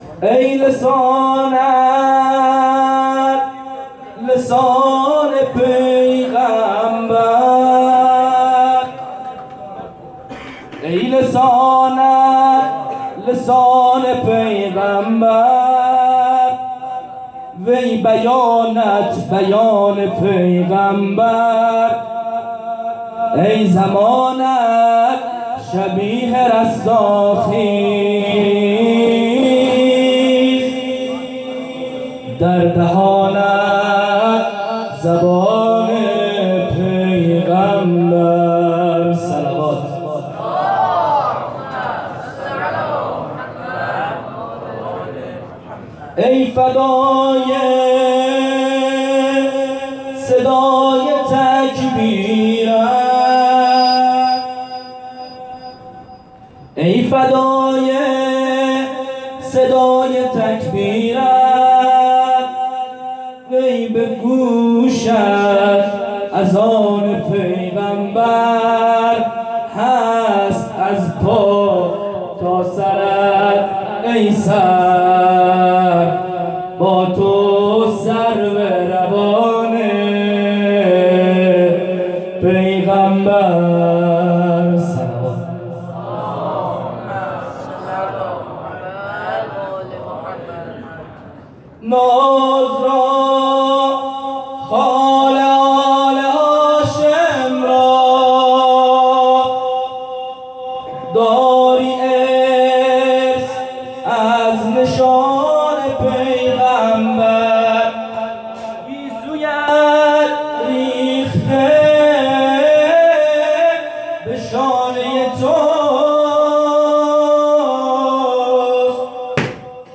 مراسم جشن نیمه شعبان 98 هیئت صادقیون(ع)زابل